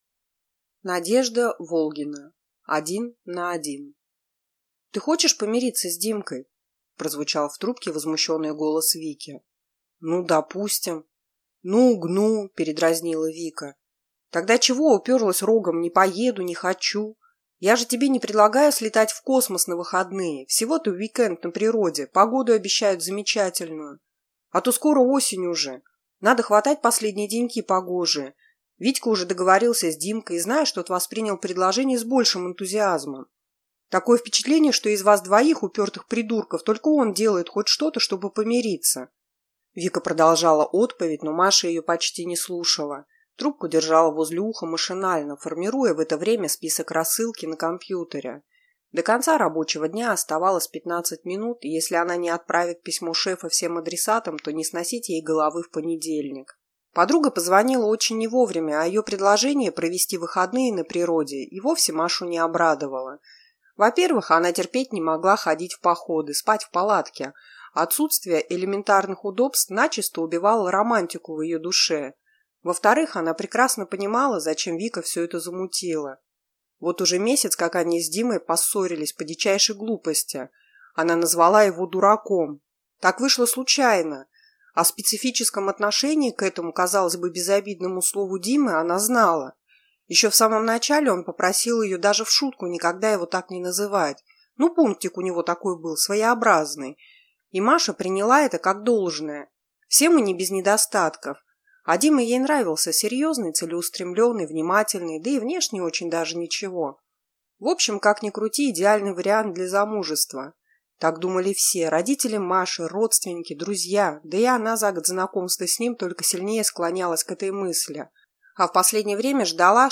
Аудиокнига Один на один | Библиотека аудиокниг
Прослушать и бесплатно скачать фрагмент аудиокниги